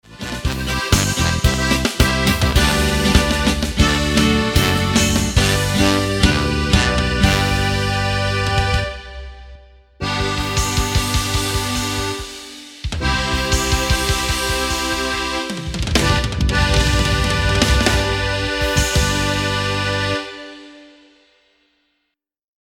• Tonart: G Dur (Originaltonart )
• Art: Bandversion – Volkstümlich
• Das Instrumental beinhaltet NICHT die Leadstimme